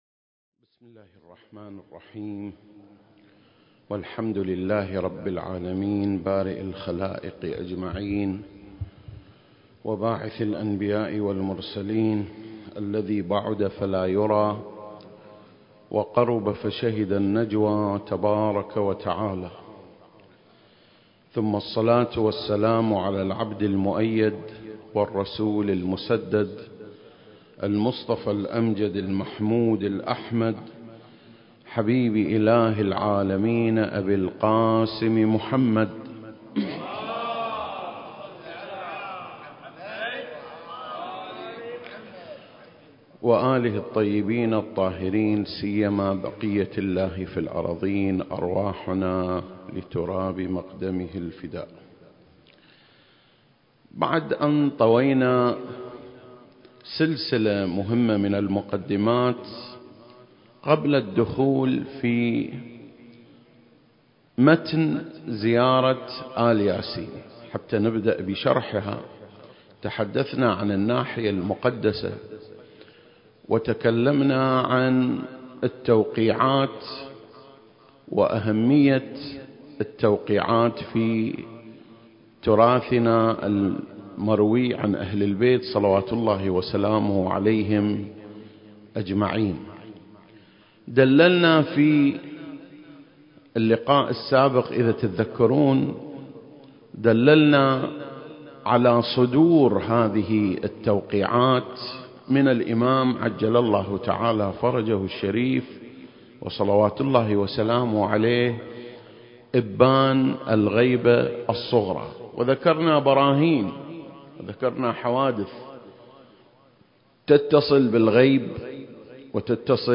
سلسلة: شرح زيارة آل ياسين (10) - صدور النص عن الإمام المهدي (عجّل الله فرجه) المكان: مسجد مقامس - الكويت التاريخ: 2021